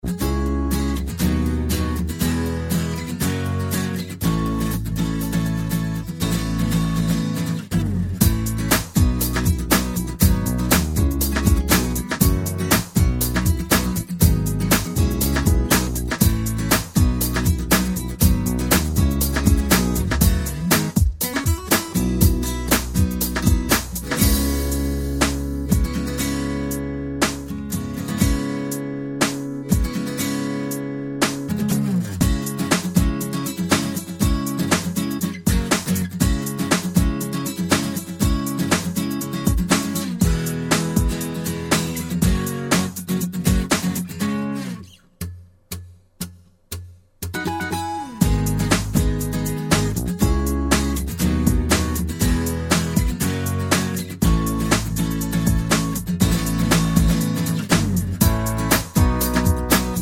Uo 3 Semitones For Male